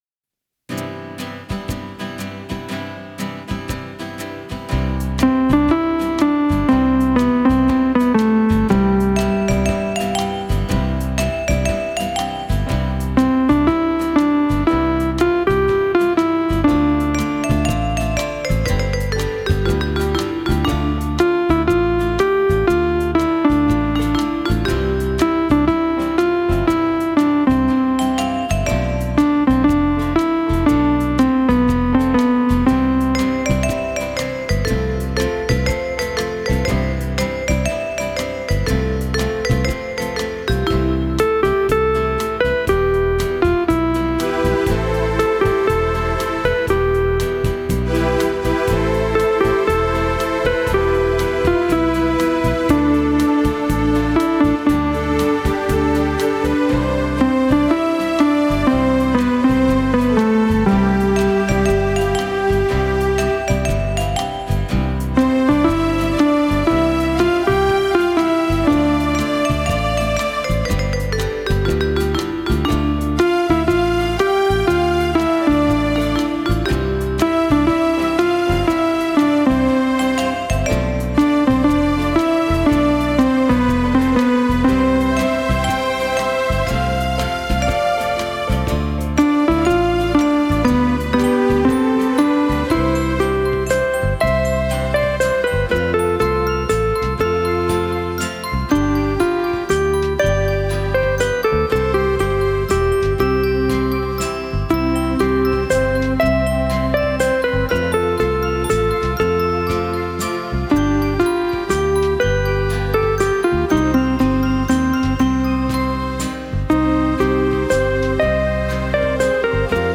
restored legacy synthesizer